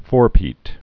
(fôrpēt)